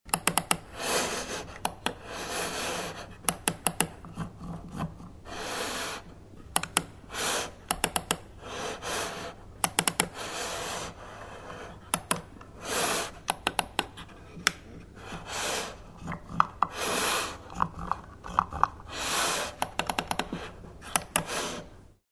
Звуки компьютерной мышки
Звук движения мышки по столу при работе